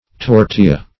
Search Result for " tortilla" : Wordnet 3.0 NOUN (1) 1. thin unleavened pancake made from cornmeal or wheat flour ; The Collaborative International Dictionary of English v.0.48: Tortilla \Tor*til"la\, n. [Sp.]